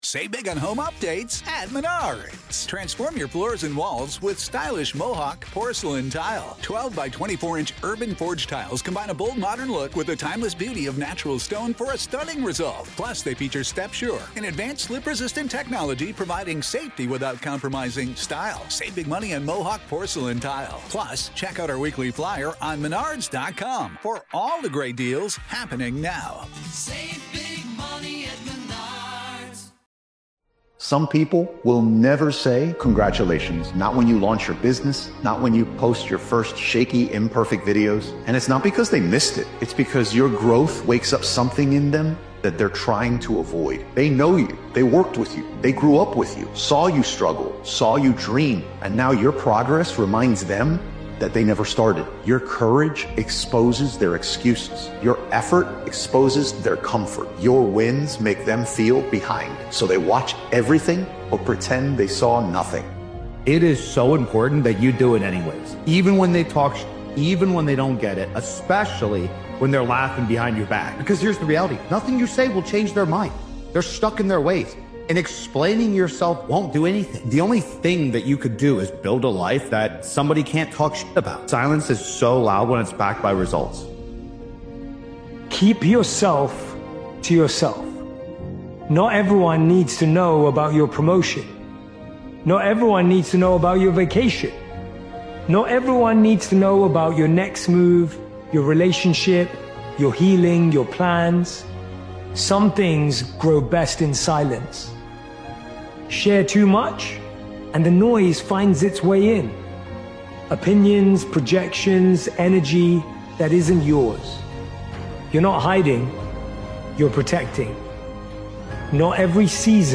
Powerful Motivational Speech is a calm but commanding motivational speech created and edited by Daily Motivations. This powerful motivational speeches compilation emphasizes the strength of quiet execution—no announcements, no distractions, just disciplined effort.